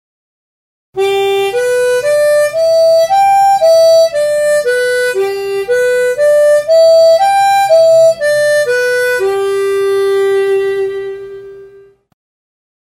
Ejercicios Prácticos para entrenamiento de la celda 2 aspirada
Ej. 7) riff de «boogie woogie» (2asp, 3asp, 4asp, 5sop, 6sop, 5sop, 4asp, 3asp y 2asp):
Ej.7-boogie-riff1.mp3